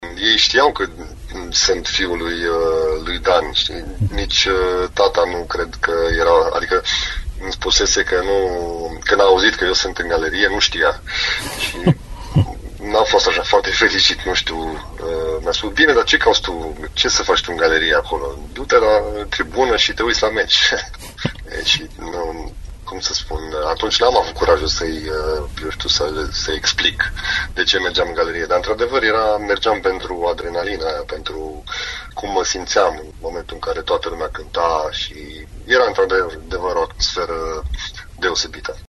Interviul complet va fi mâine, la ediția de sâmbătă a Arenei Radio.